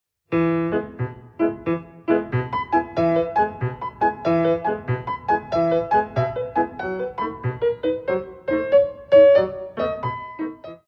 4x8 - 6/8